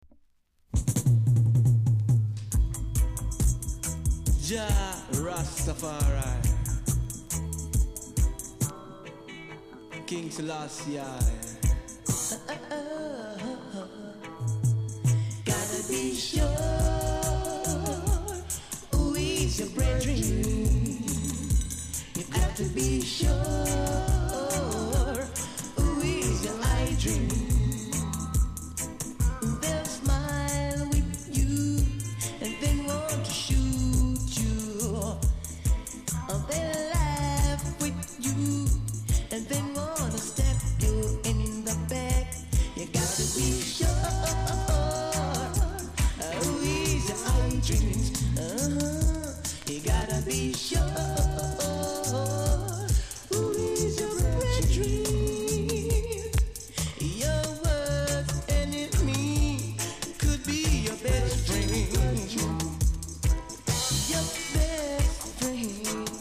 ※小さなチリノイズが少しあります。
コメント DEEP 80's!!!YELLOW WAX